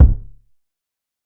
TC2 Kicks24.wav